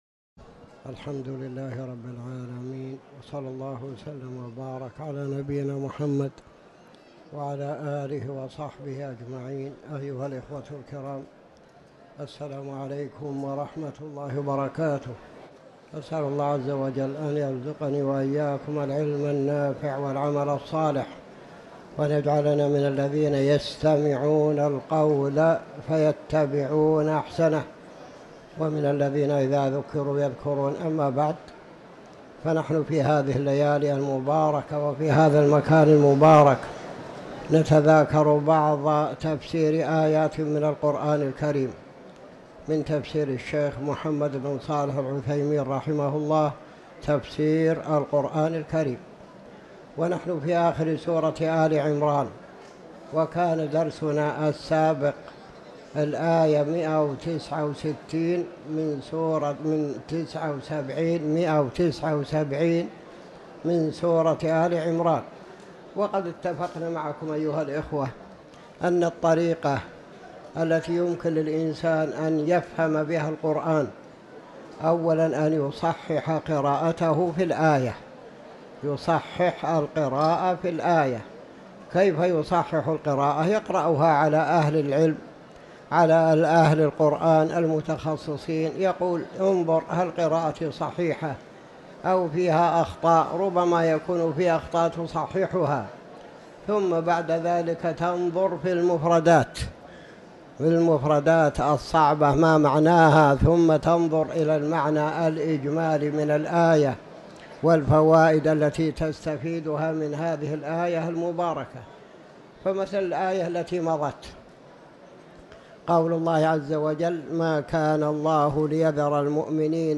تاريخ النشر ٤ رجب ١٤٤٠ هـ المكان: المسجد الحرام الشيخ